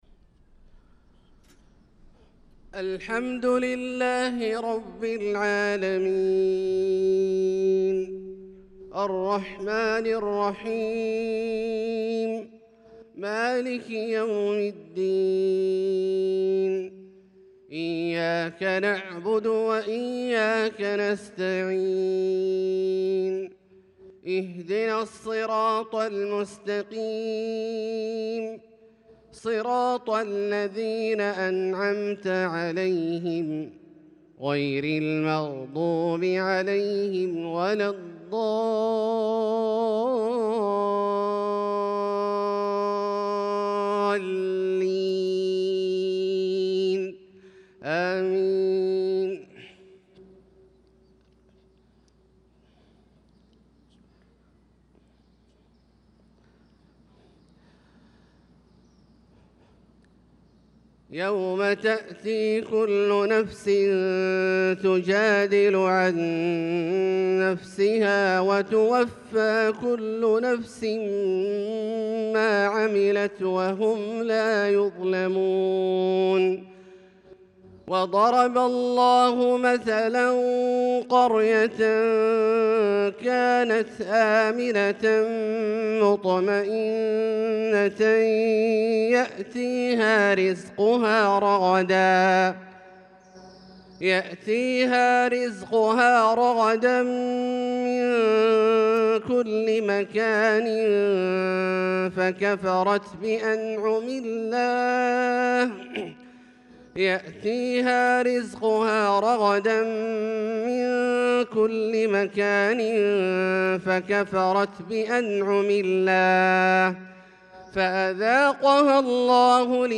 صلاة الفجر للقارئ عبدالله الجهني 15 شوال 1445 هـ